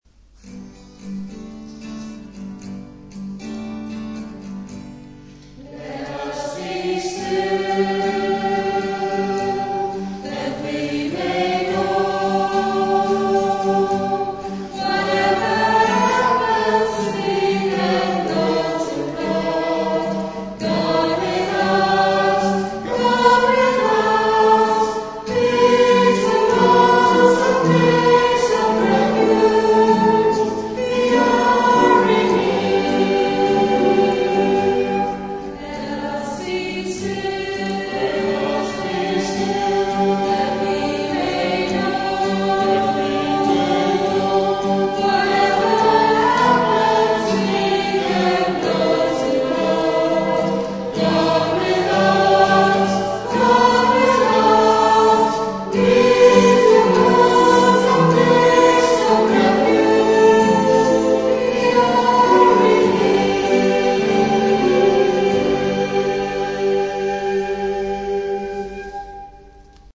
Shorter, more meditative, songs